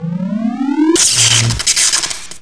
Tesla Coil: Primary Weapon of Soviet